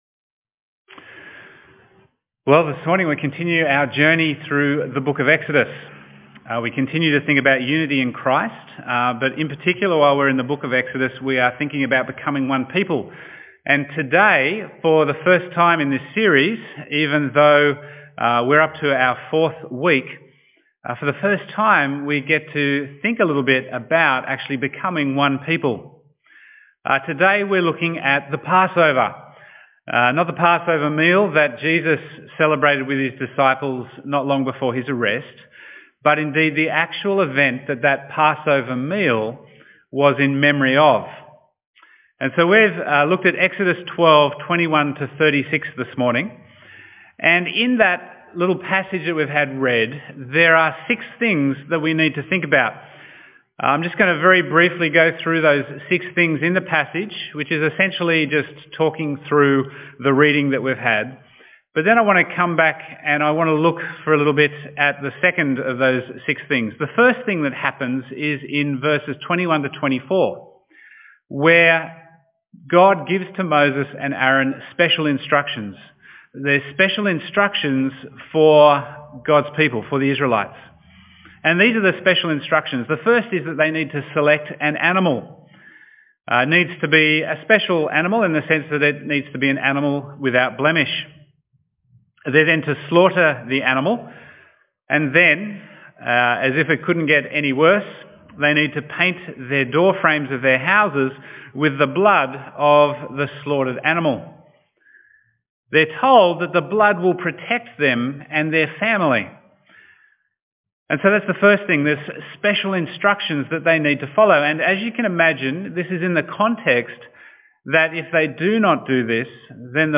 Bible Text: Exodus 12:21-36 | Preacher